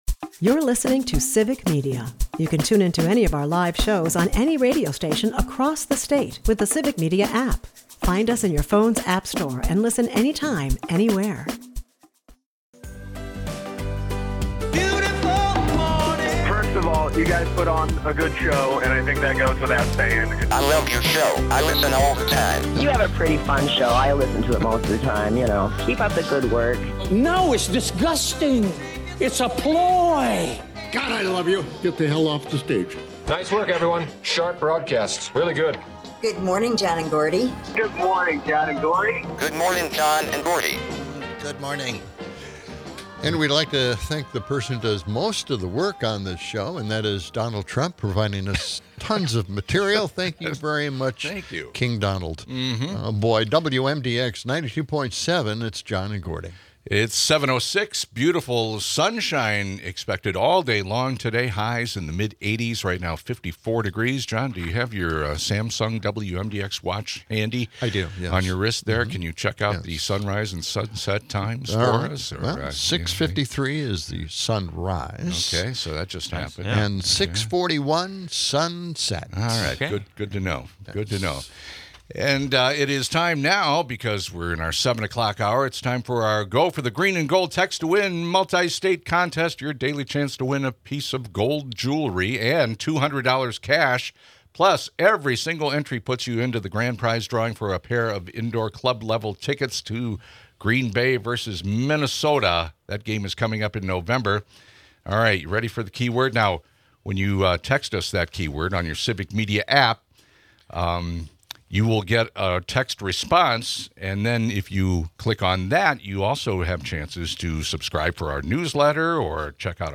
The hosts critique a Rutgers poll on political violence and skewed perceptions fueled by propaganda.